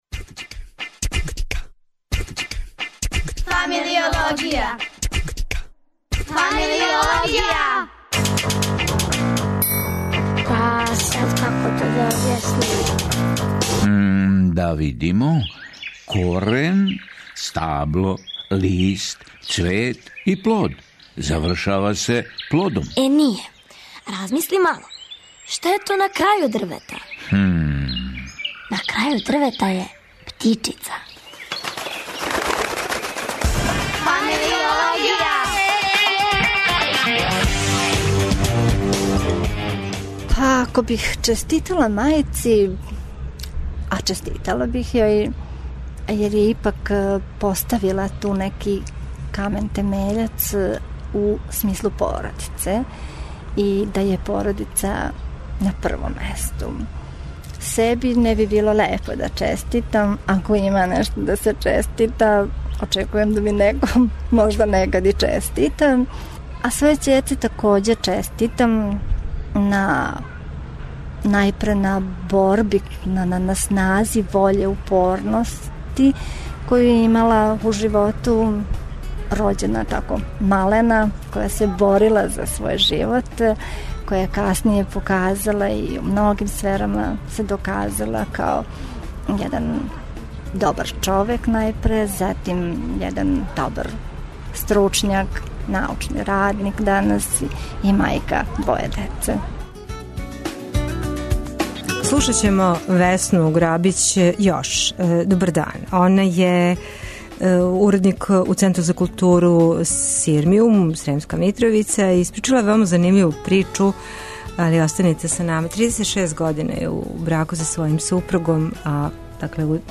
Осим њене приче, чућемо и стоматолога, психолога и ендокринолога...